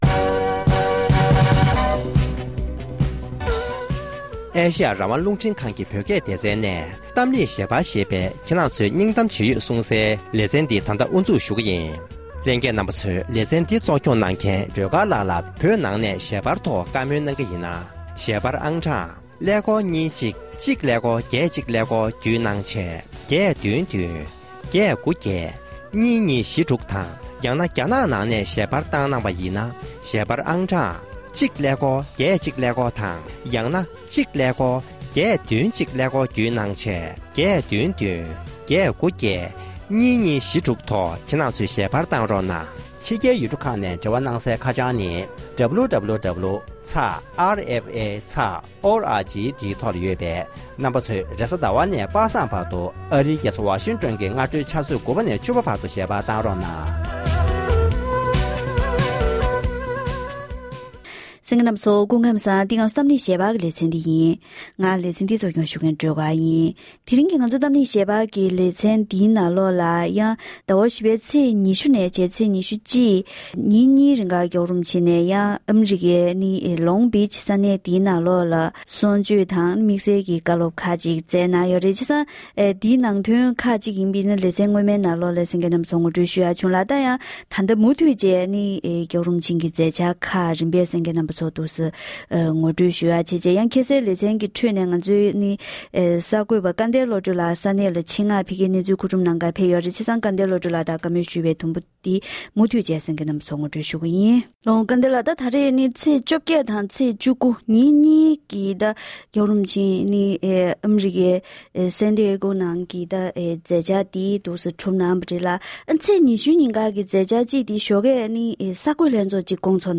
འབྲེལ་ཡོད་མི་སྣར་གླེང་མོལ་ཞུས་པར་གསན་རོགས༎